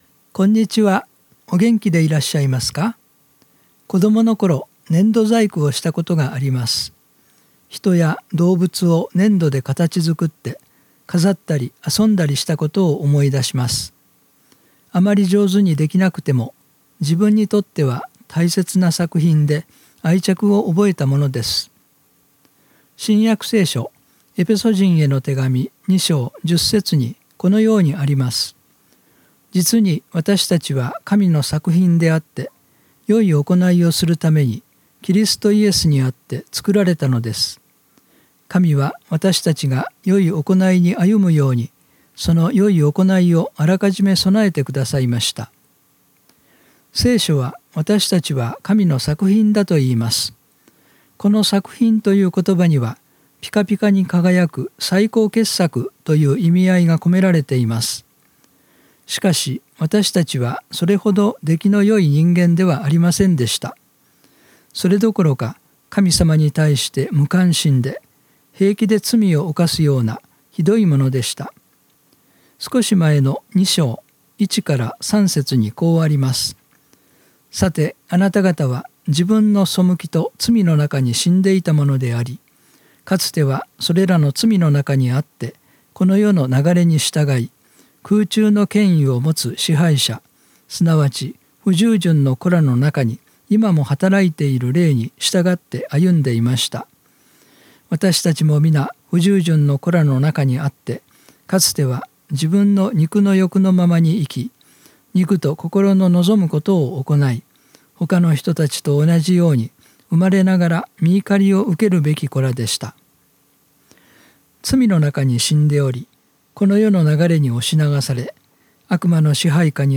電話で約３分間のテレフォンメッセージを聞くことができます。